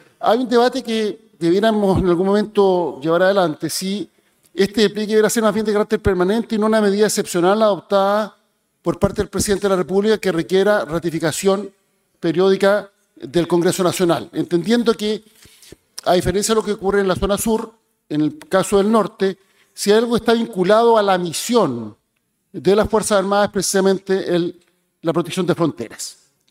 Durante el debate, el ministro del Interior, Álvaro Elizalde, defendió la eficacia de la medida, destacando una reducción del 34,2 % en ingresos irregulares a nivel nacional en 2025, y un descenso del 23,3 % en Colchane.